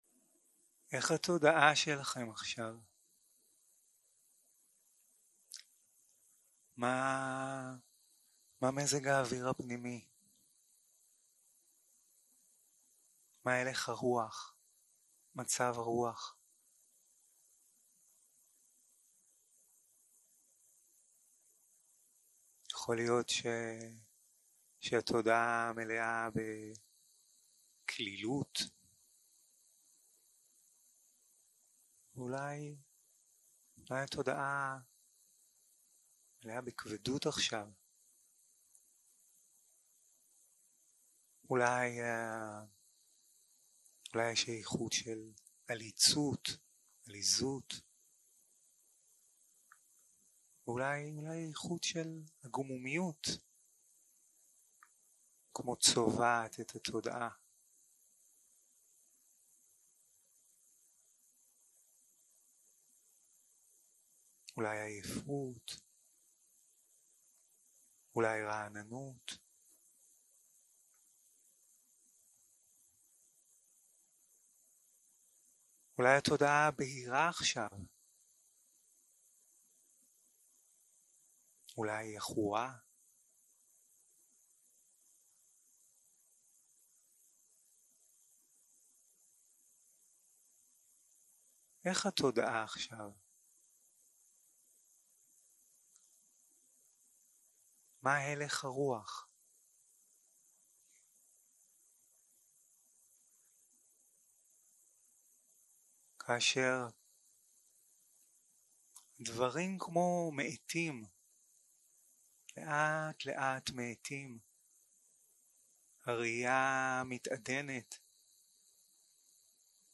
יום 6 - הקלטה 15 - בוקר - הנחיות למדיטציה